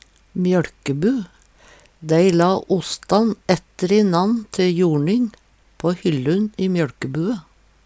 DIALEKTORD